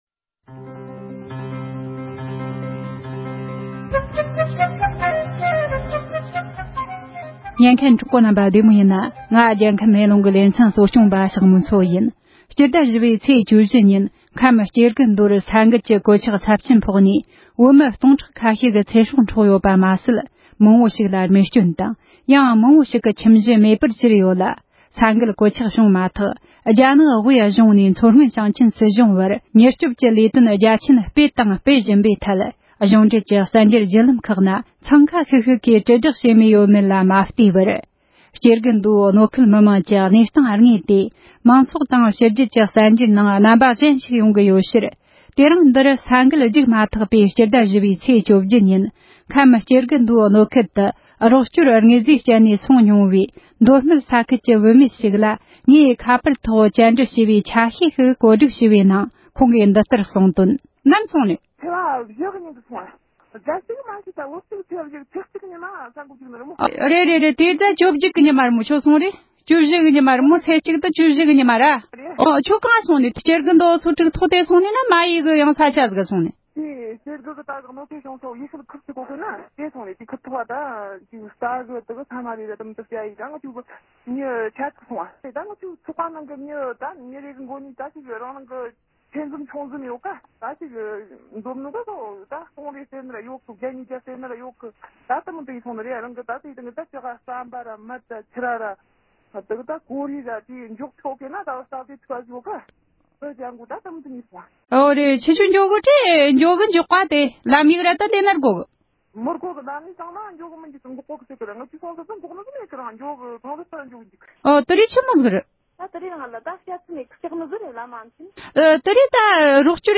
སྐབས་དེར་སྐྱེ་རྒུ་མདོར་རོགས་སྐྱོར་དངོས་པོ་བསྐྱལ་བར་འགྲོ་མྱོང་མཁན་ཨ་མདོའི་བུད་མེད་ཅིག་དང་མཉམ་དུ